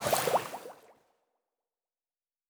Fantasy Interface Sounds
Potion and Alchemy 11.wav